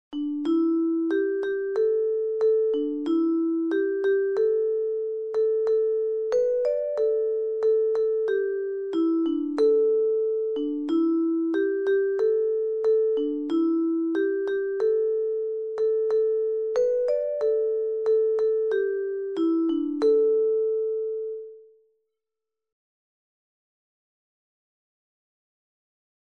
Allen zingen: